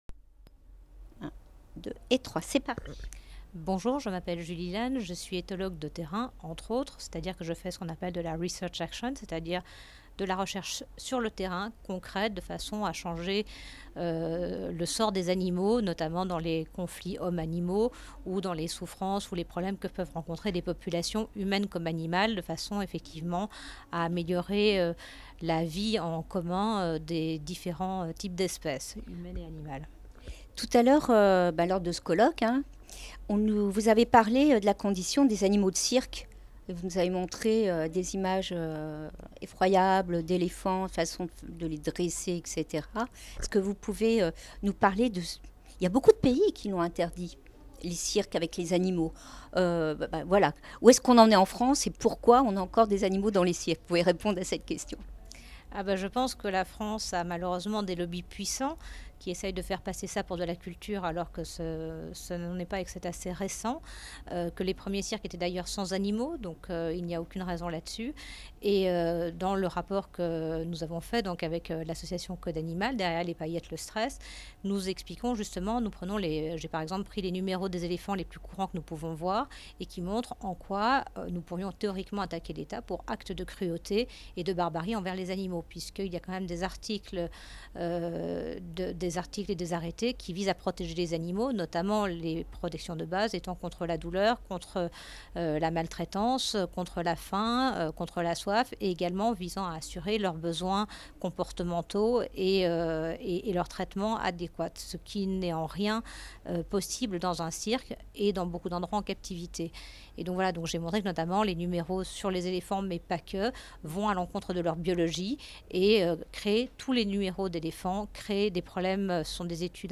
Entretien
Entretien réalisé